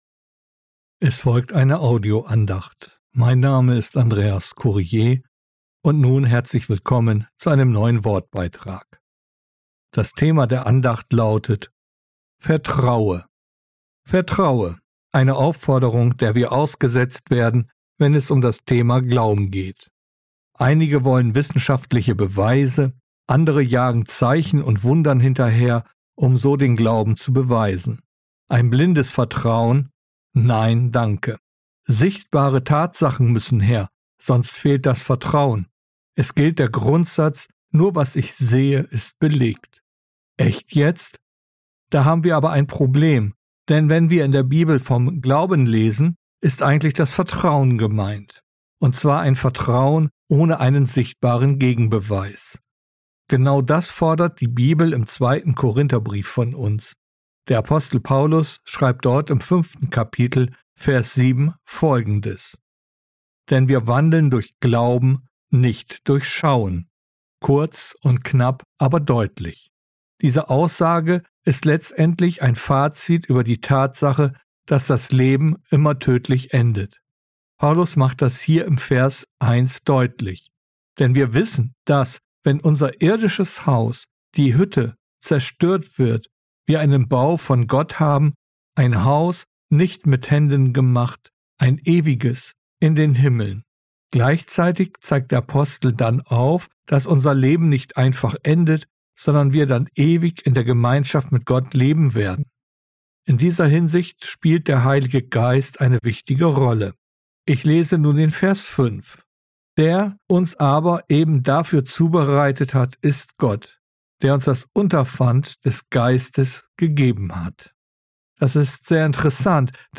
Vertraue! Eine Audio-Andacht